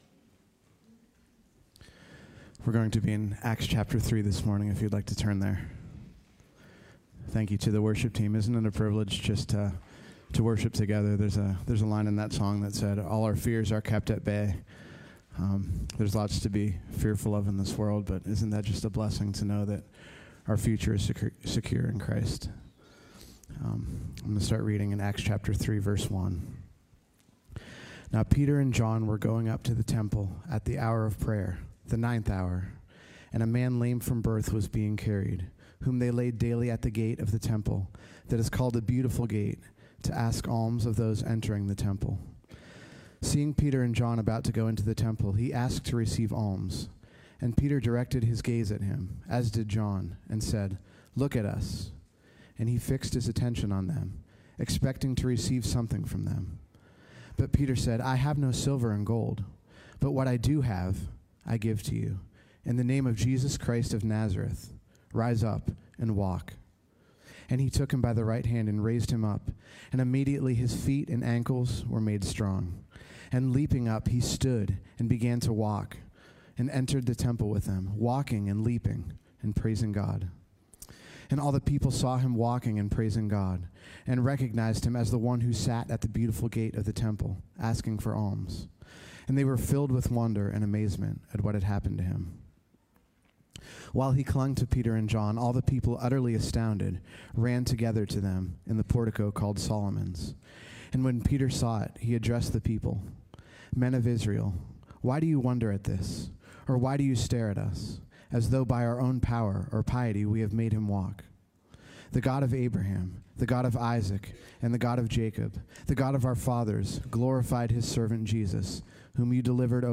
Sermons through the book of Acts